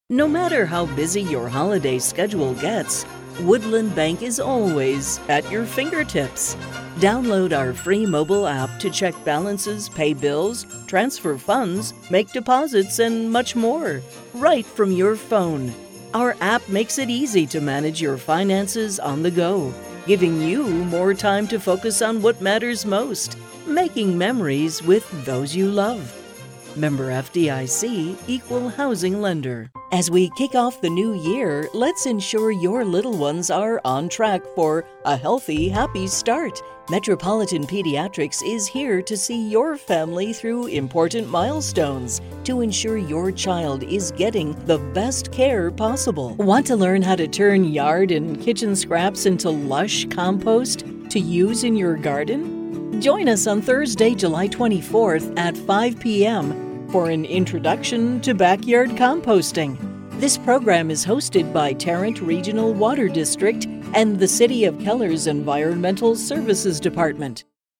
On Hold Demo